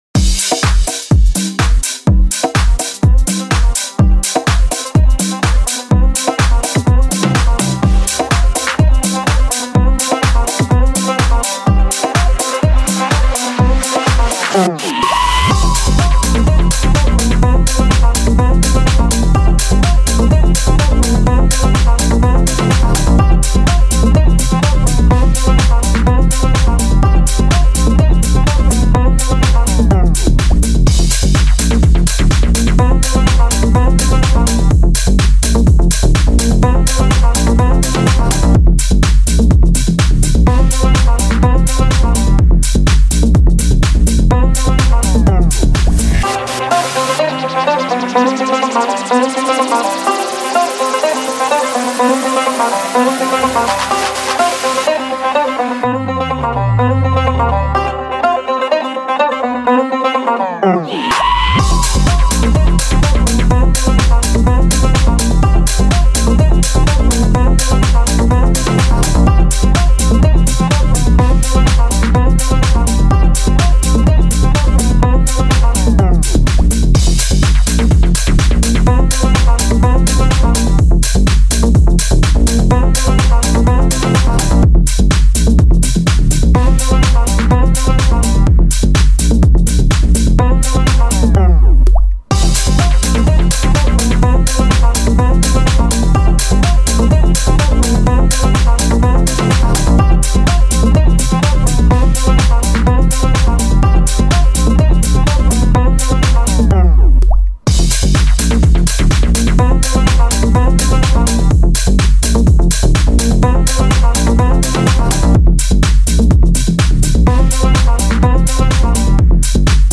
Genre - TECH HOUSE
BPM - 125